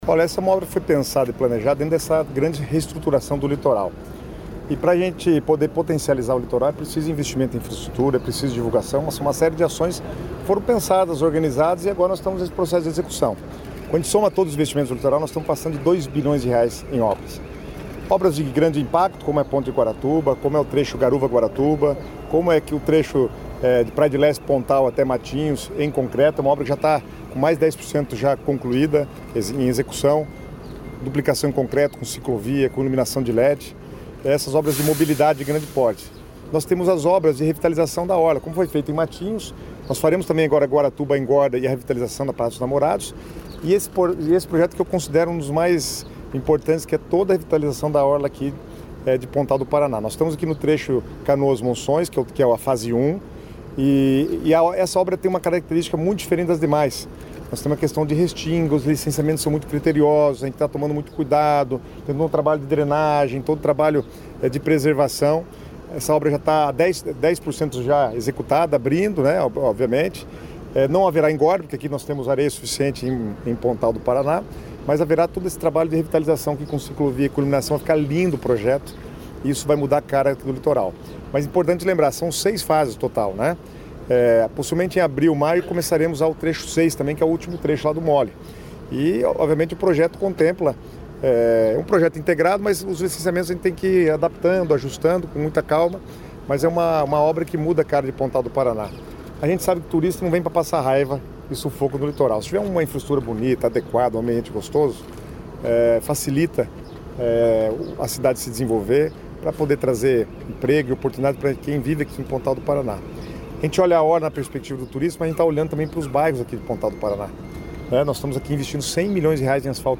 Sonora do secretário das Cidades, Guto Silva, sobre as obras de requalificação da orla de Pontal do Paraná vão melhorar balneários